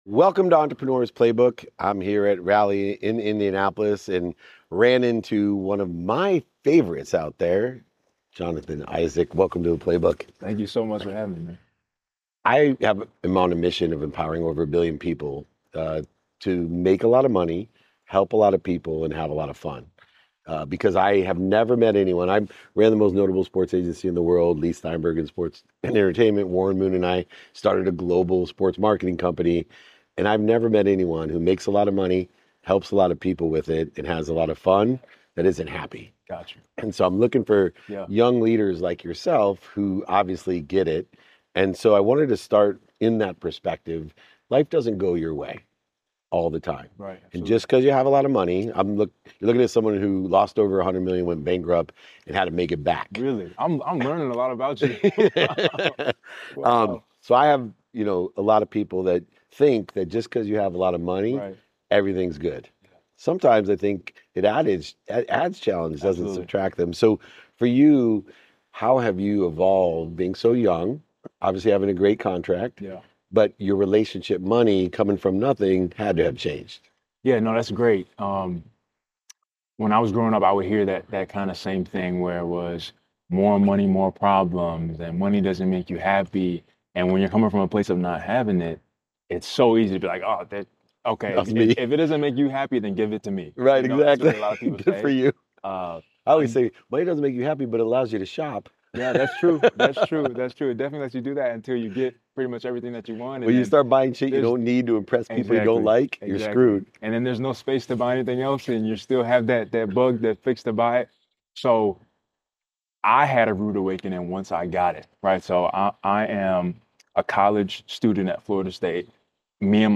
In today’s episode, I sit down with an NBA player who redefined greatness through faith and purpose.